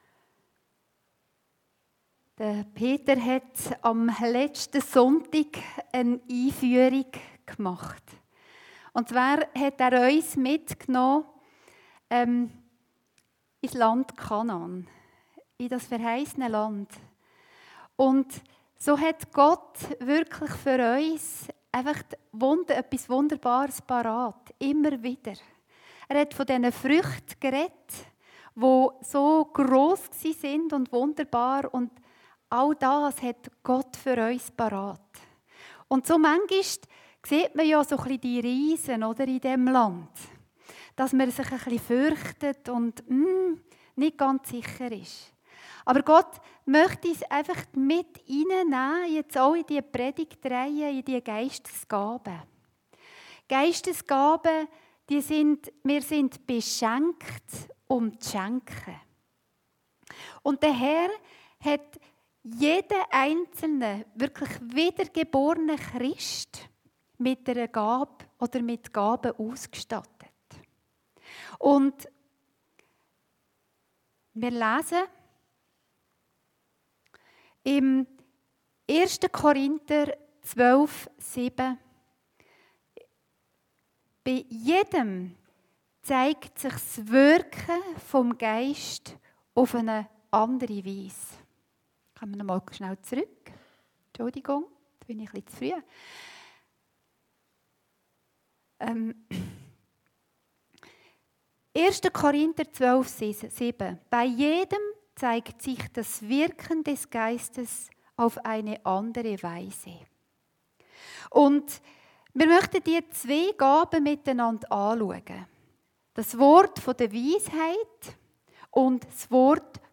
Predigten Heilsarmee Aargau Süd – Die Gabe des Wortes der Weisheit und der Erkenntnis